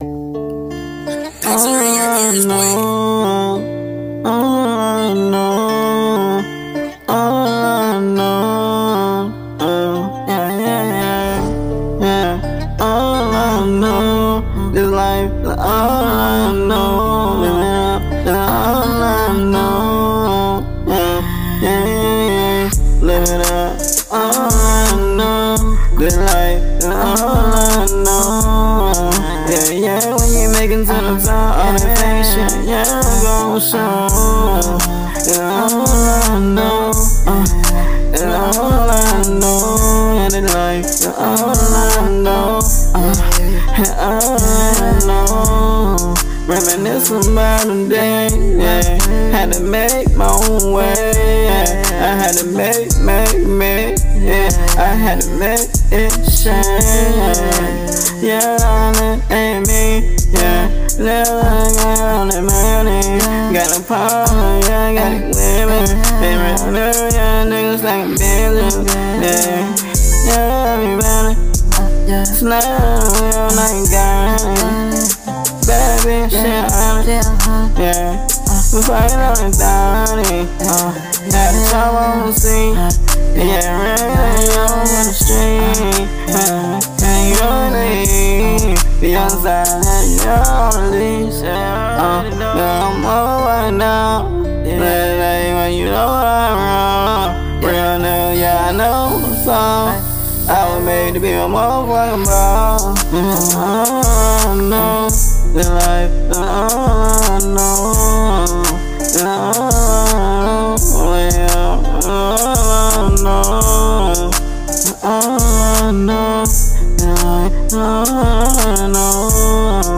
Alternative